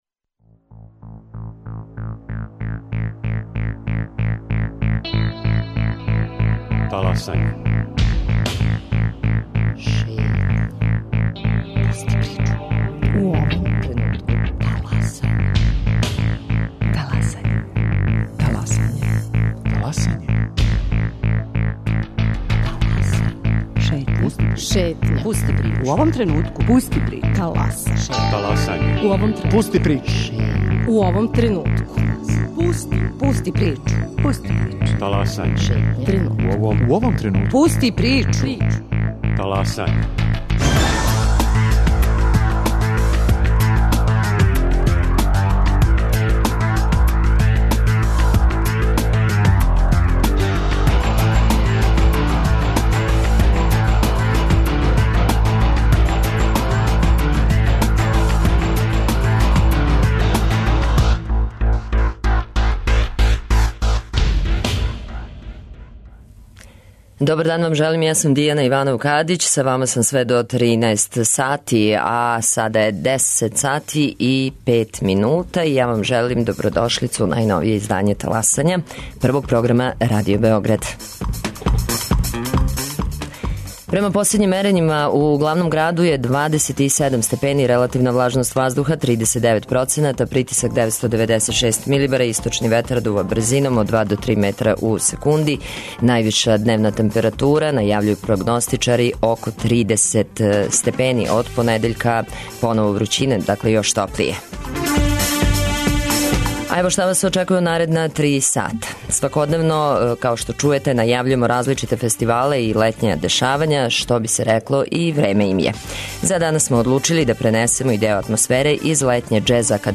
Шетајући новосадским улицама ових дана, из Средње музичке школе "Исидор Бајић" у Новом Саду, иако је распуст, могли су се чути звуци клавира, трубе, тромбона, бубњева...